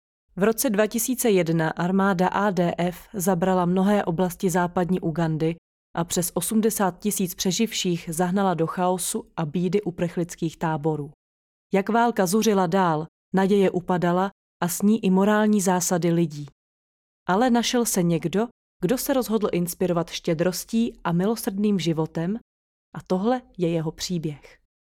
Umím: Voiceover
Ukázka VO video - Generosity in the Midst of War_VO woman CZ_01 mnau.mp3